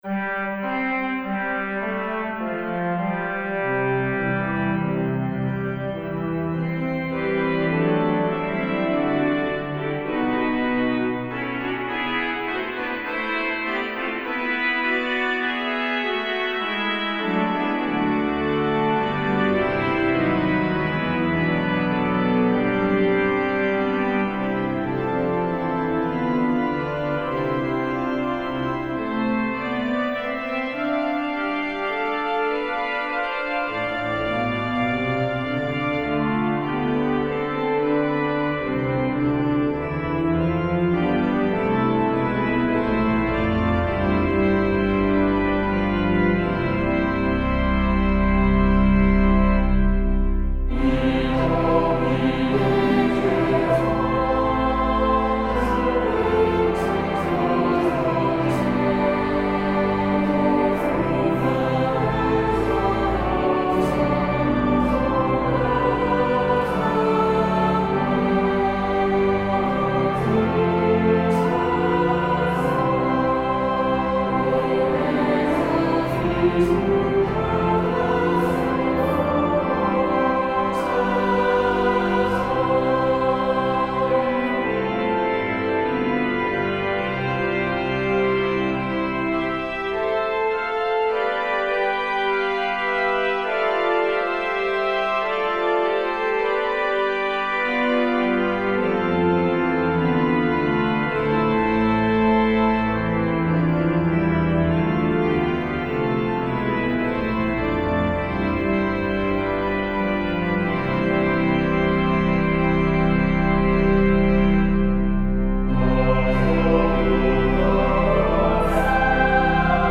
Free descant to the hymn tune DARWALL'S 148TH - 'Ye holy angels bright'